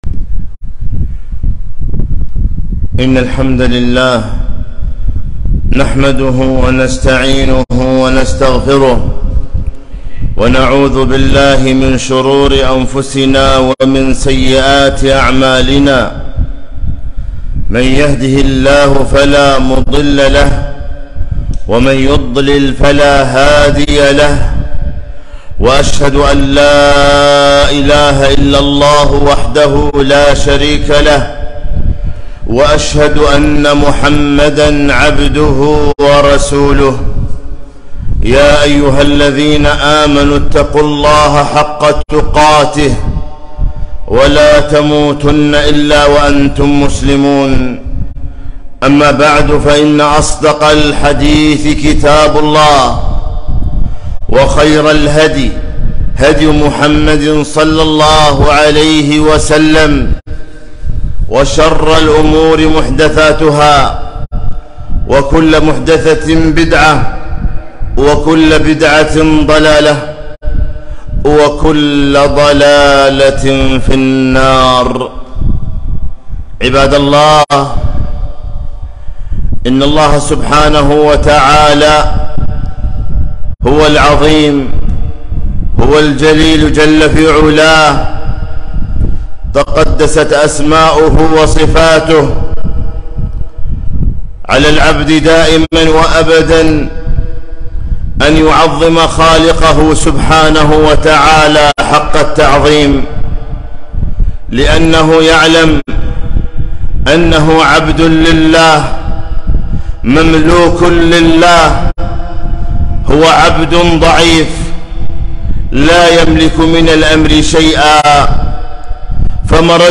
خطبة - ( تعظيم حرمات الله)